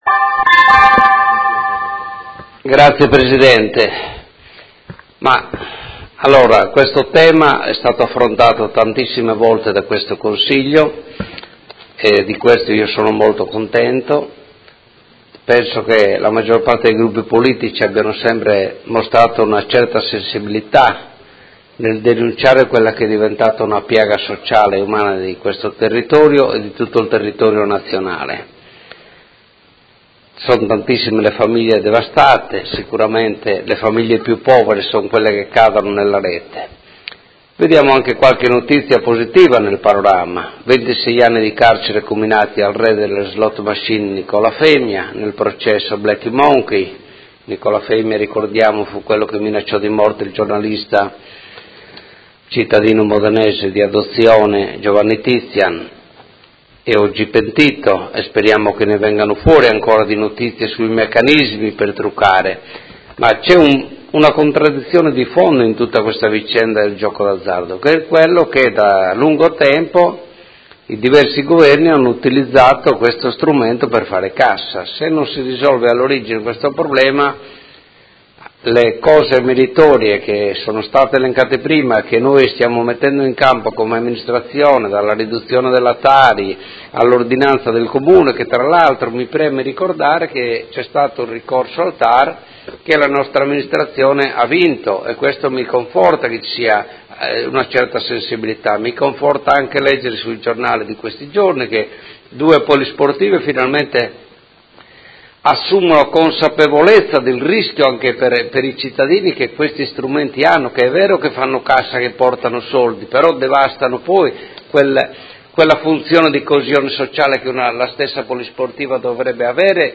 Seduta del 8/6/2017 Dibattito. Ordini del giorno su "Giochi d'azzardo"